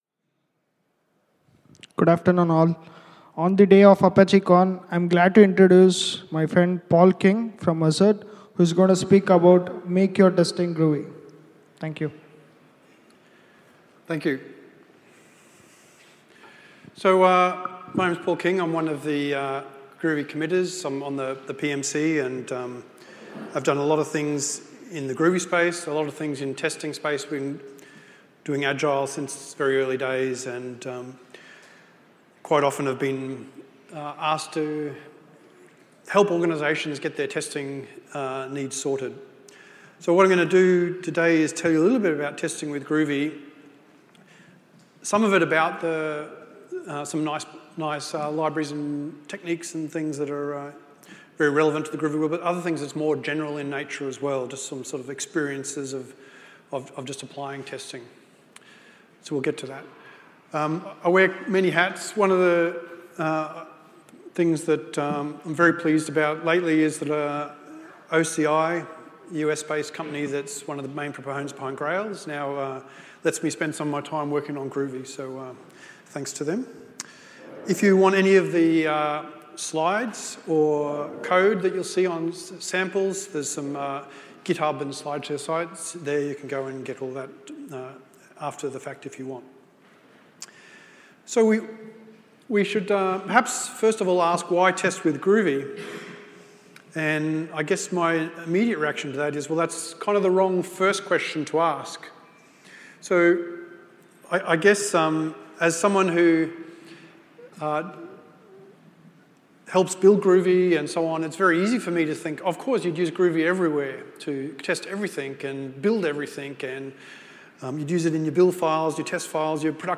ApacheCon Seville 2016
This talk explores using the Groovy scripting language as the basis for Agile testing. It covers tools, libraries and techniques for Unit and BDD style testing.